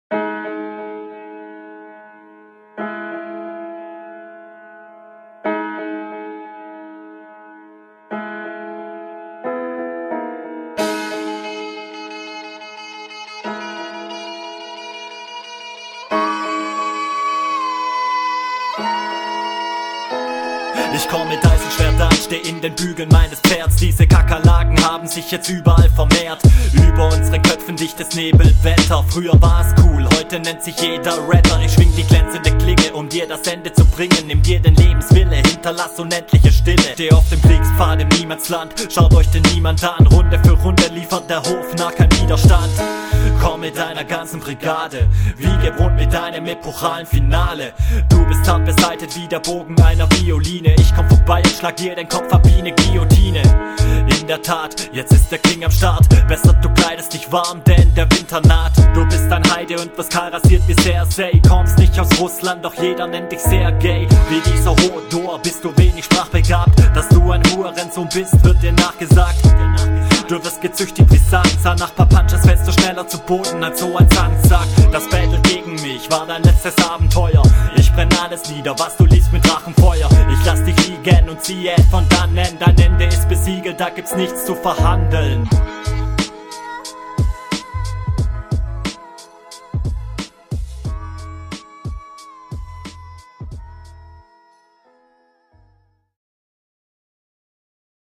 Flowlich durchgehend gut und abwechslungsreich.
Hättest deine Stimme ruhig bisschen lauter machen können.